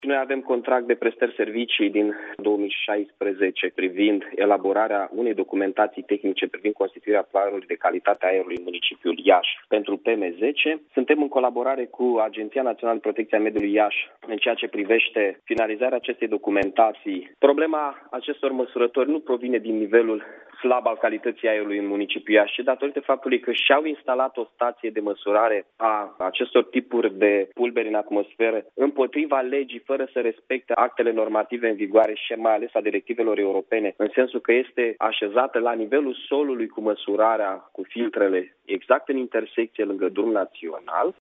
În replică, primarul Iaşului, Mihai Chirica, a explicat, pentru postul nostru de radio, că municipalitatea colaborează cu Agenţia Naţională pentru Protecţia Mediului Iaşi în vederea elaborării unui plan de măsuri pentru îmbunătăţirea calităţii aerului.